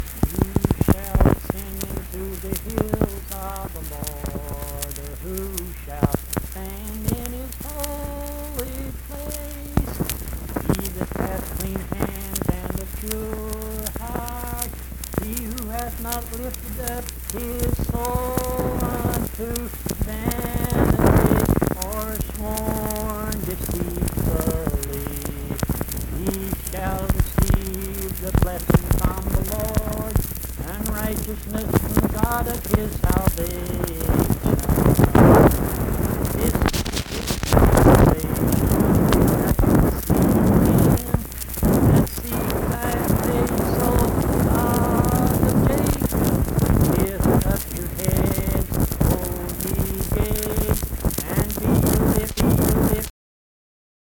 Performed in Mount Harmony, Marion County, WV.
Hymns and Spiritual Music
Voice (sung)